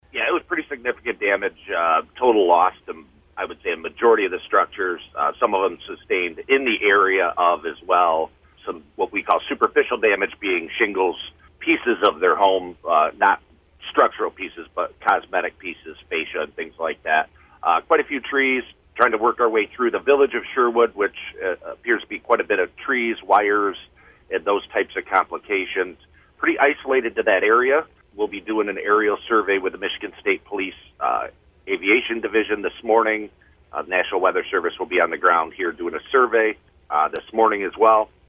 Miner spoke to WTVB News the morning after the storms.